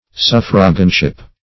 Suffraganship \Suf"fra*gan*ship\